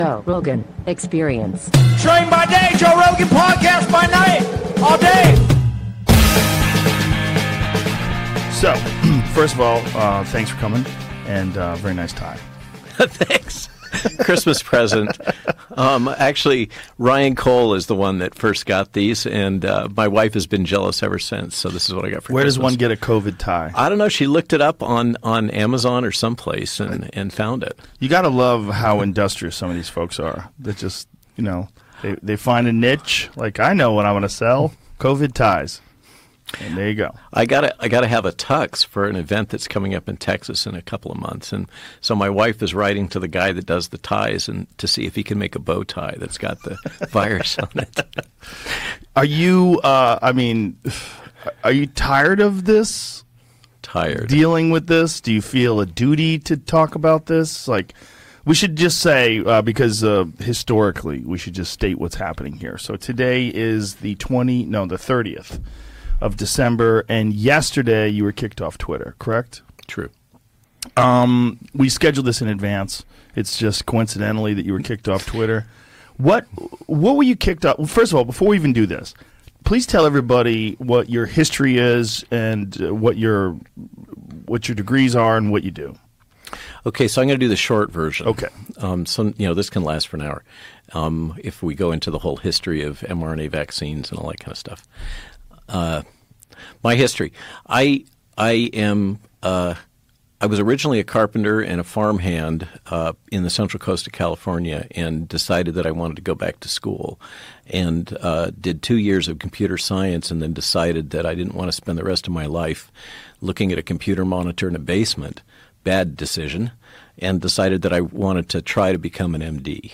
Das wichtigste Interview unserer Zeit? Robert Malone bei Joe Rogan